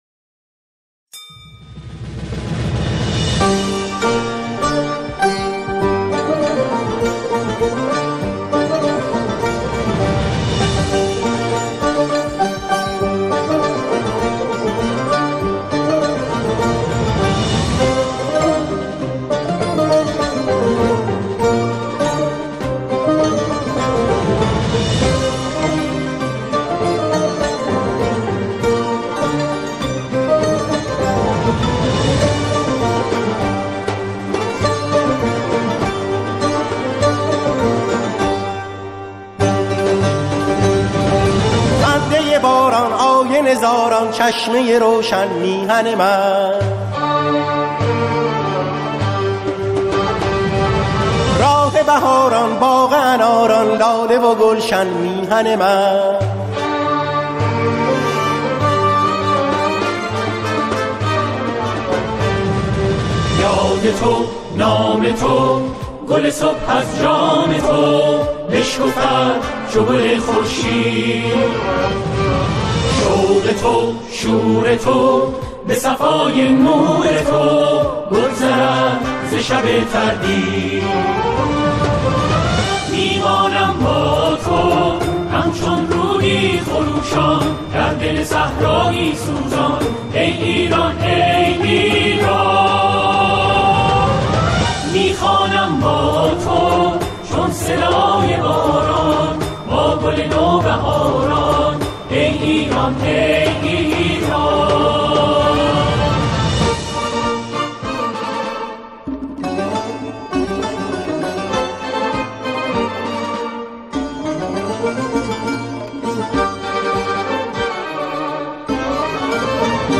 در این قطعه، او، شعری را با موضوع ایران همخوانی می‌کند.